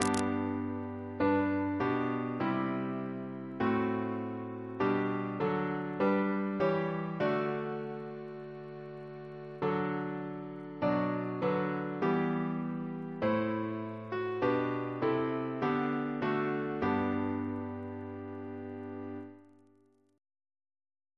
Double chant in G